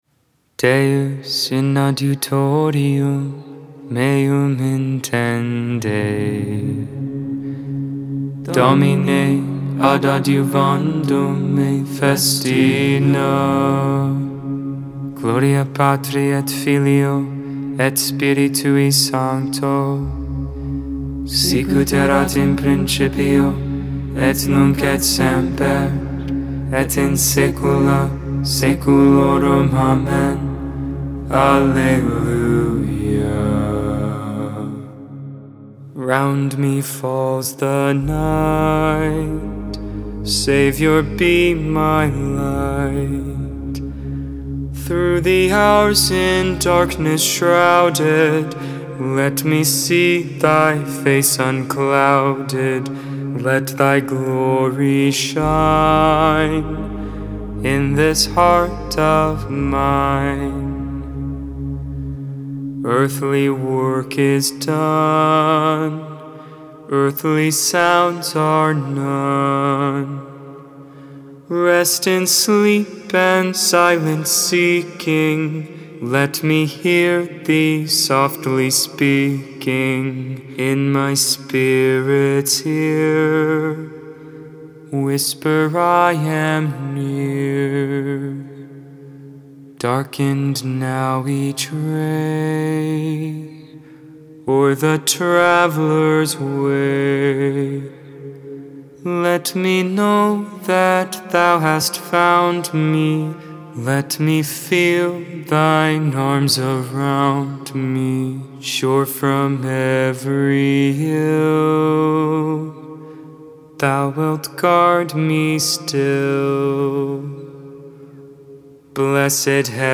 2.1.23 Vespers, Wednesday Evening Prayer